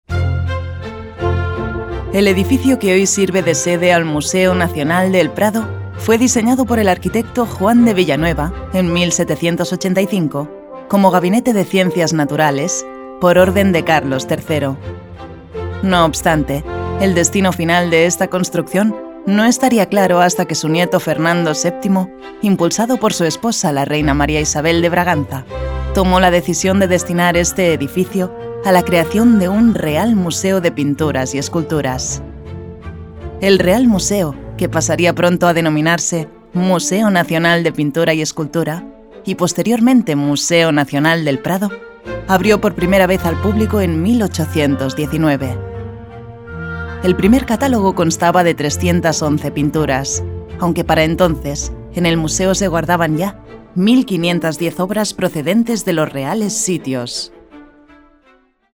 kastilisch
Sprechprobe: Industrie (Muttersprache):